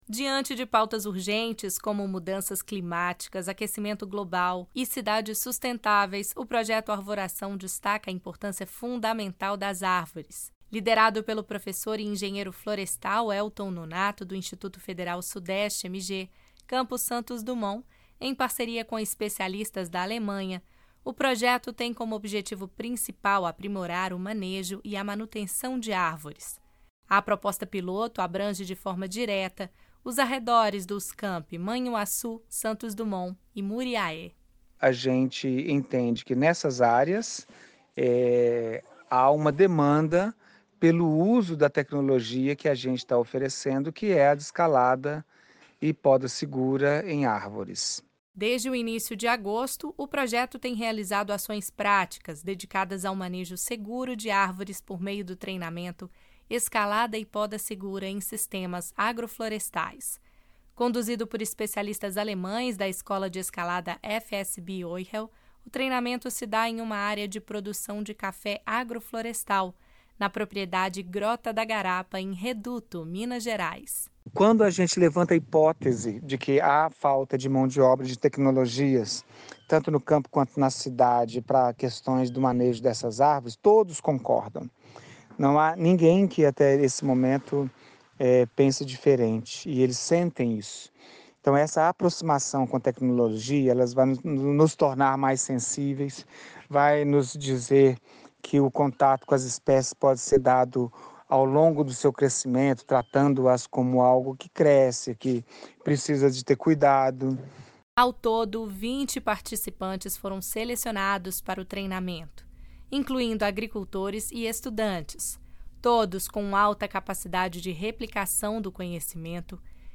REPORTAGEM - PROJETO ARVORAÇÃO.mp3
reportagem-projeto-arvoracao.mp3